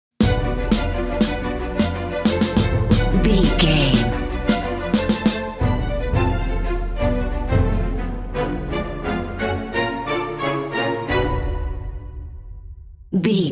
A mysterious orchestral track with a middle eastern vibe.
Aeolian/Minor
dark
eerie
mystical
suspense
cello
double bass
violin
percussion
flutes
oboe
harp
orchestra
trumpet
brass